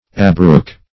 Abrook \A*brook"\, v. t.